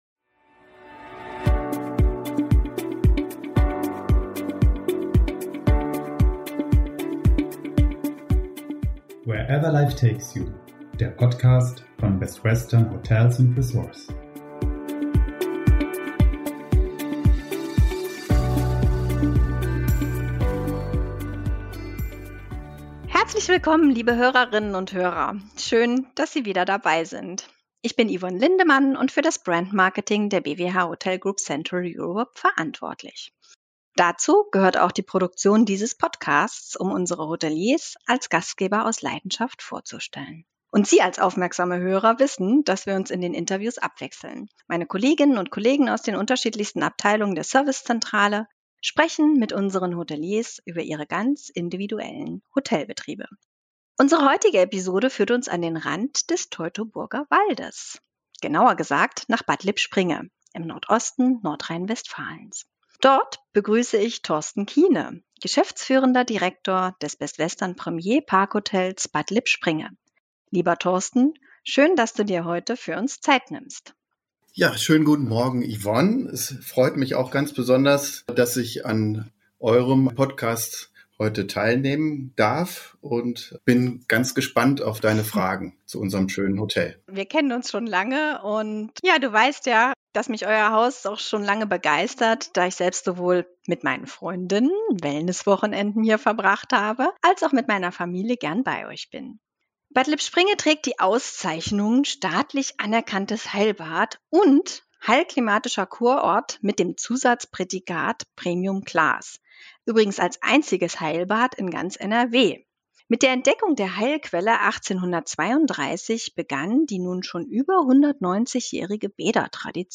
Beschreibung vor 3 Jahren Aufatmen, Loslassen, mit allen Sinnen genießen: Damit kennen sich unsere Interview-Gäste bestens aus.